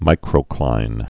(mīkrō-klīn)